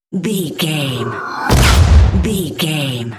Airy whoosh explosion hit
Sound Effects
futuristic
intense
woosh to hit